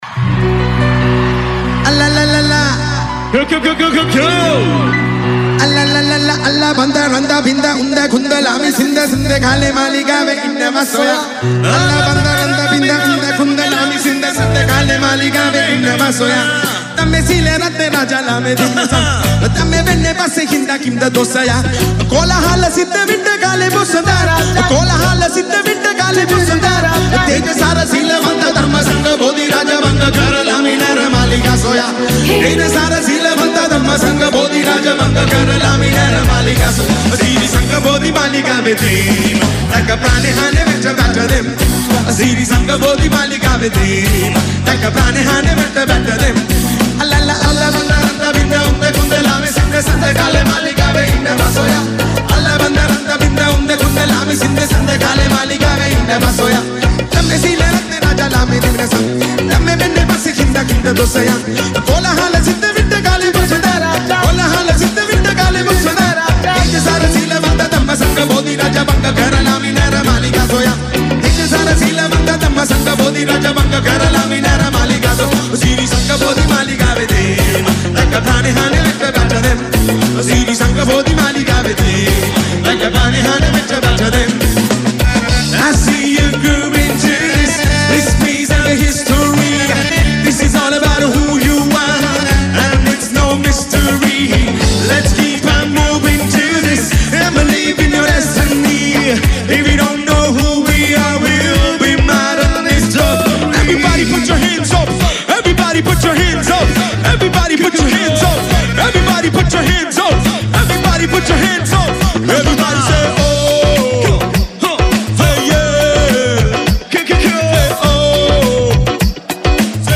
Category: Live Shows